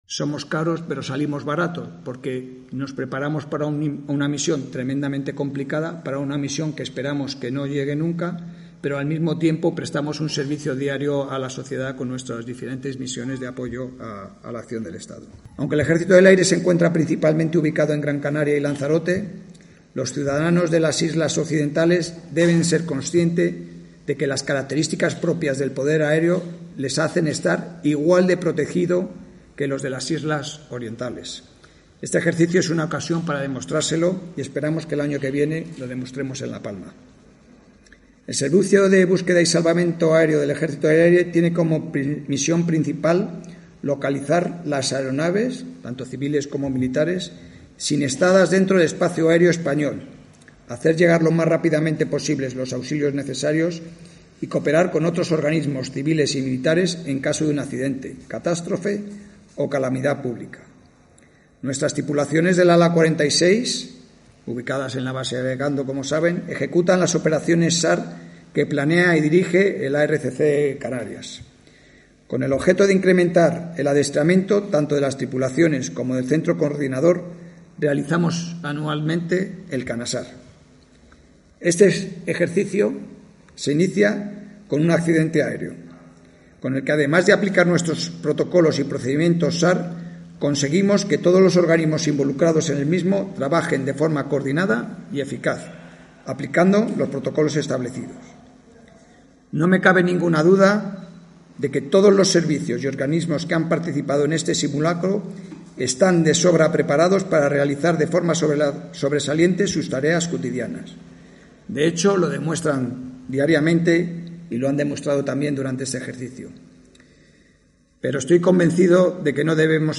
Los Realejos. Acto clausura CANASAR 2022 – valoraciones y resultados. 2 de junio – Gente Radio